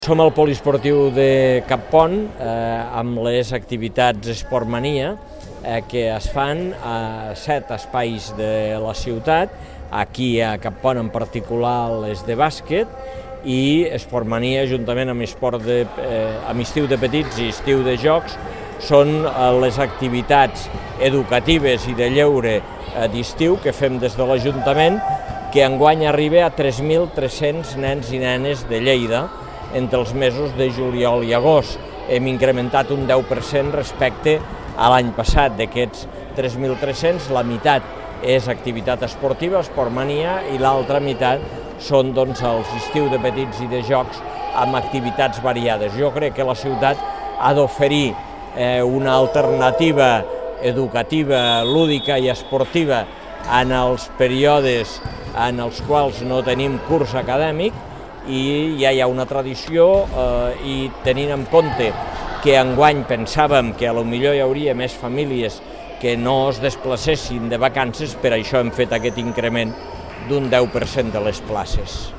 Tall de veu de l'alcalde de Lleida, Àngel Ros, sobre la visita a Esportmania al pavelló de Cappont.
tall-de-veu-de-lalcalde-de-lleida-angel-ros-sobre-la-visita-a-esportmania-al-pavello-de-cappont